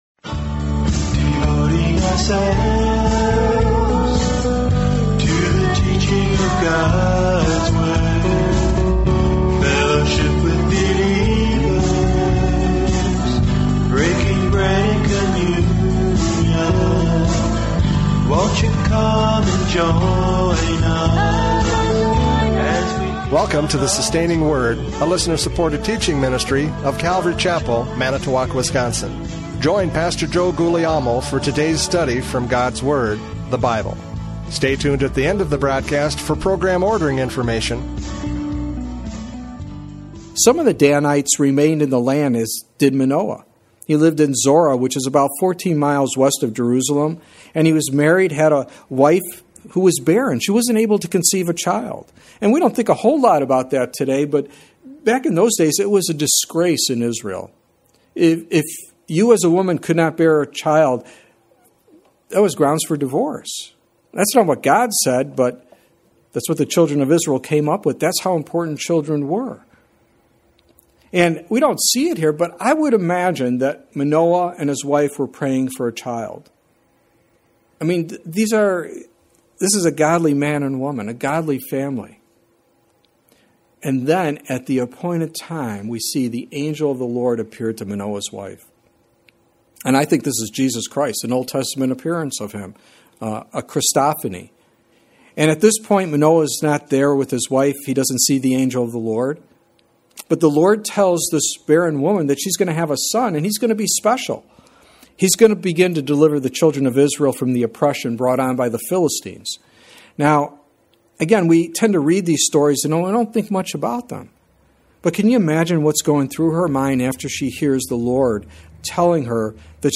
Judges 13 Service Type: Radio Programs « Judges 13 A Light That Flickered!